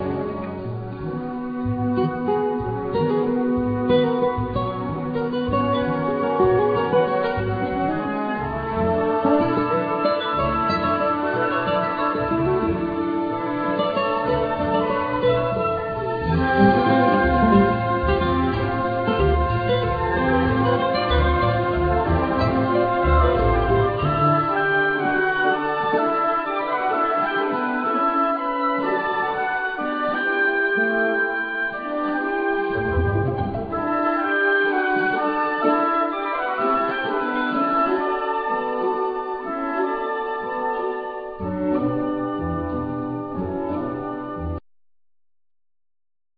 Classical&12 string guitar,Piano,synths
Oboe,Soprano sax,Bass Clarinet
Acoustic bass
Drums,Percussion